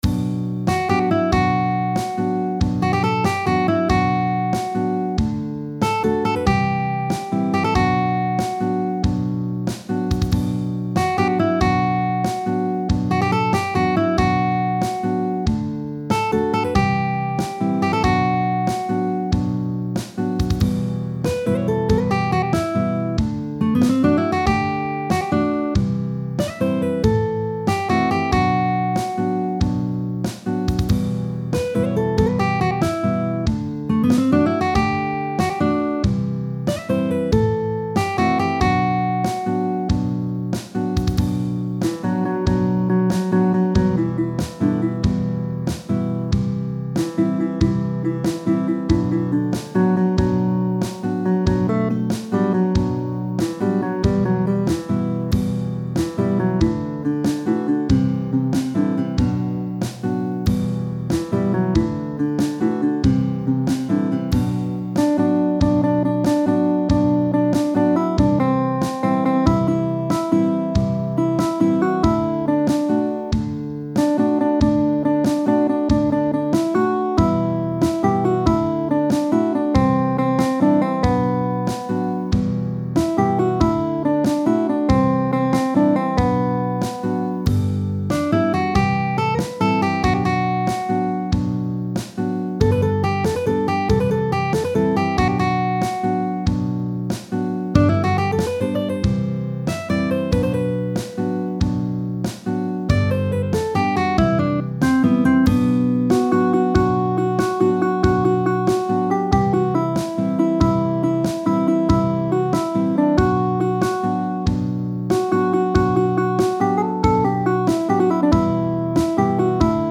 در گام اصلی Bm دو مینور و ریتم ۶/۸
اجرای ملودی گیتار